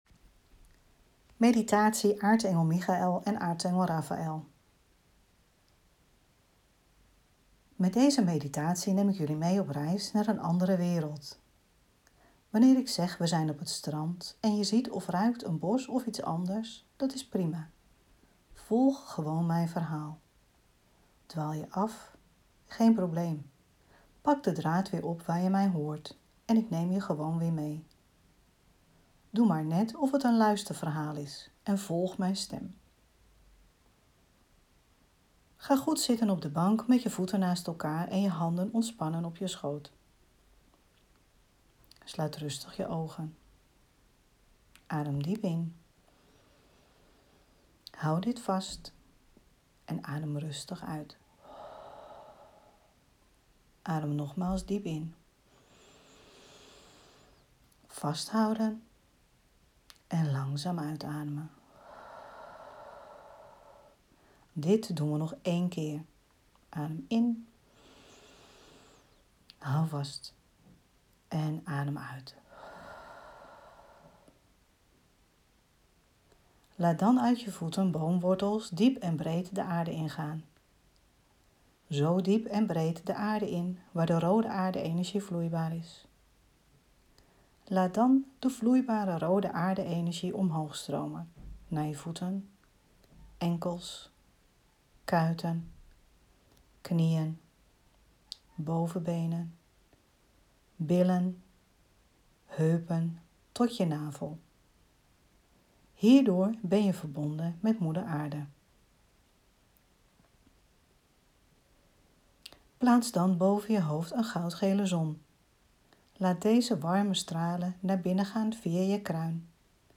ae-michel-en-ae-rafael-meditatie.mp3